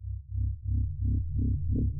low_pulse_1.R.wav